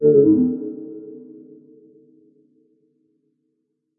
warning_open.ogg